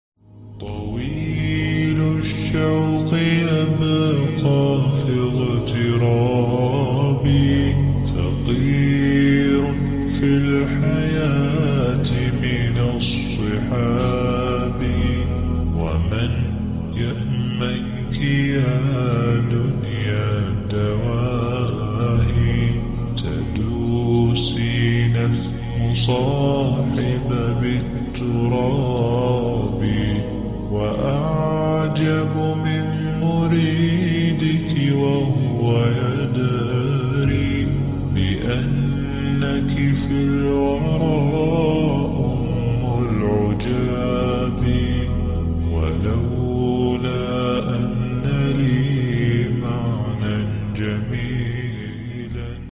Enjoy this peaceful Islamic Nasheed.